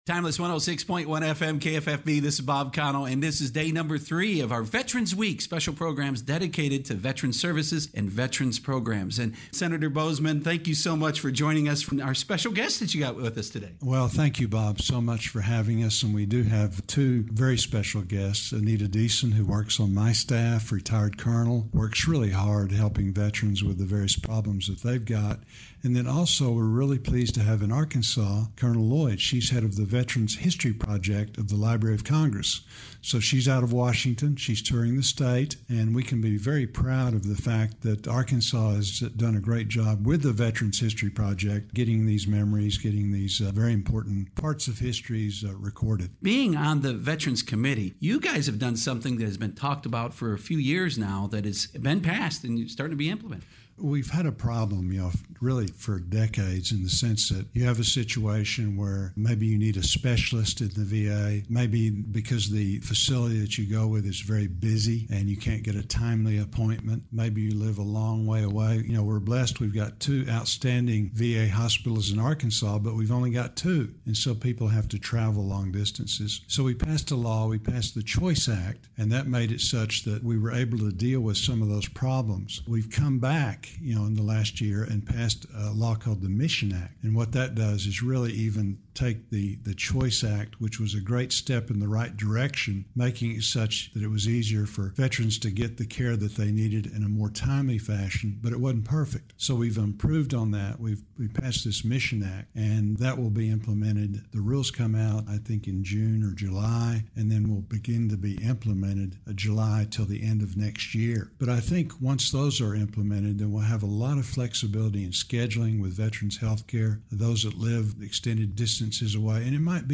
U.S. Senator John Boozman (R-AR) on KFFB’s Open Mic Day #3 for Veterans Week, November 12th-16th. The interview program